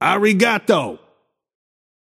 Shopkeeper voice line - Arigato.
Shopkeeper_hotdog_t4_yamato_01.mp3